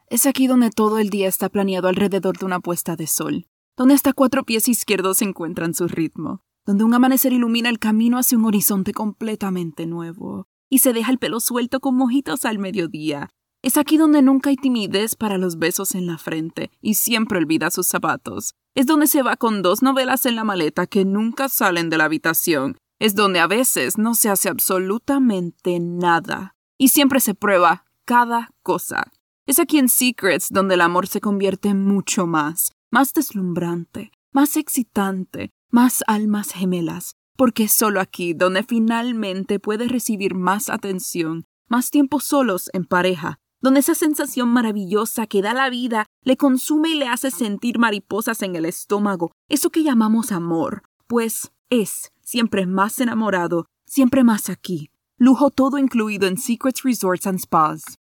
Narration
Ma voix combine naturellement chaleur, clarté et énergie dynamique, ce qui en fait un choix parfait pour les projets qui nécessitent un son relatable et professionnel.